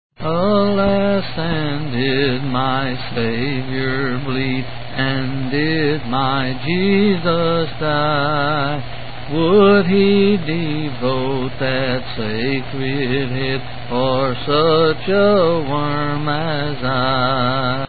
C. M.